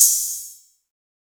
HiHat (26).wav